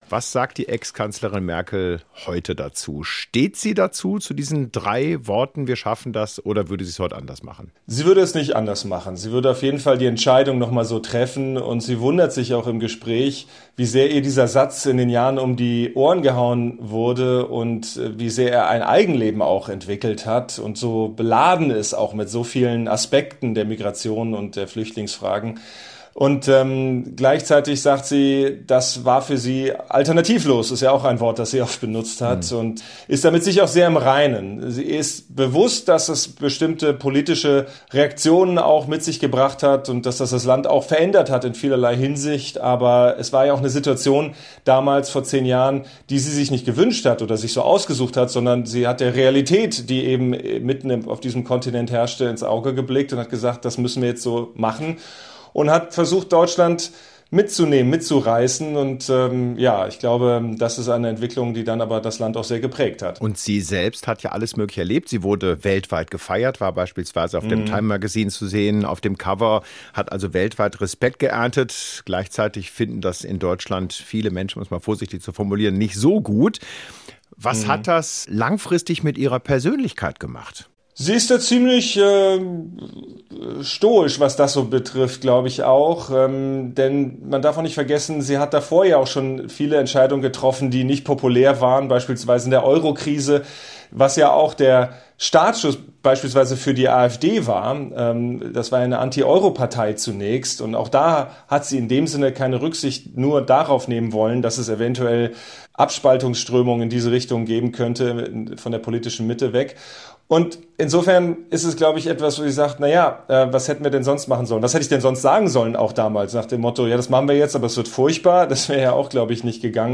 Wir haben mit Tagesthemen-Moderator Ingo Zamperoni über seine Doku "Merkels Erbe – 10 Jahre "Wir schaffen das!" gesprochen.